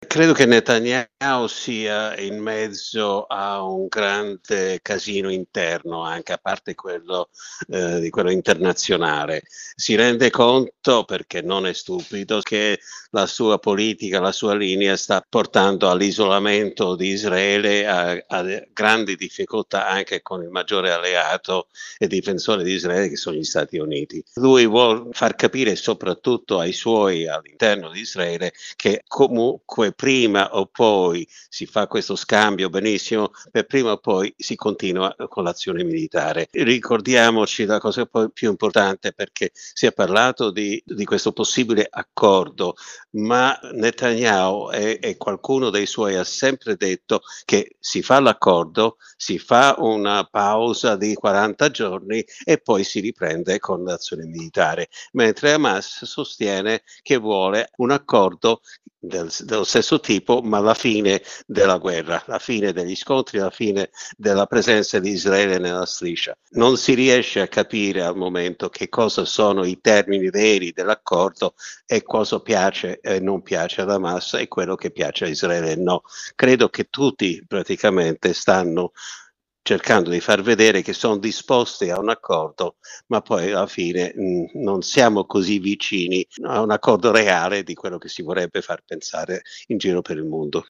giornalista, scrittore, a lungo inviato nella regione